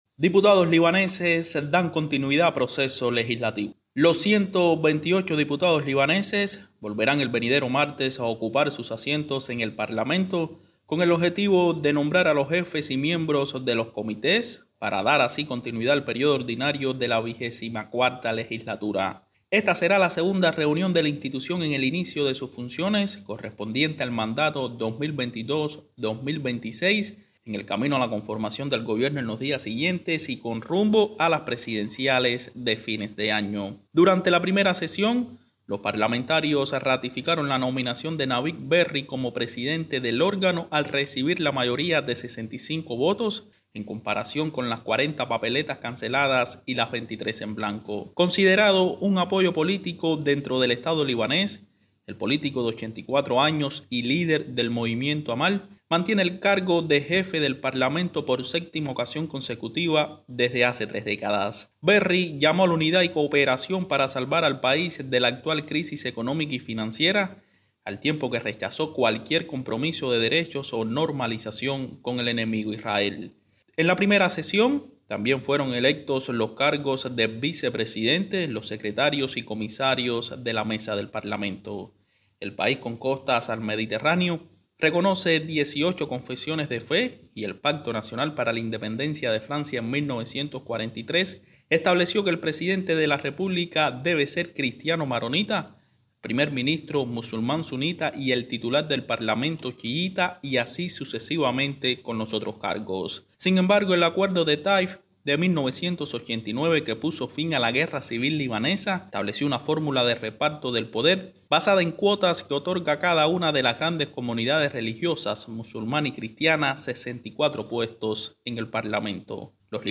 desde Beirut